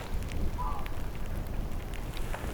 tuollainen lokin ääni, 4
tuollainen_lokin_aani_4.mp3